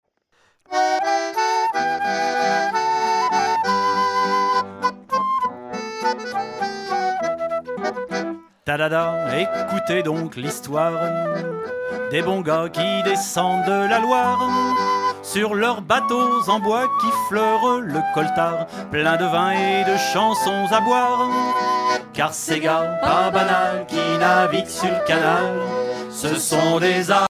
Genre strophique
Concert donné en 2004
Pièce musicale inédite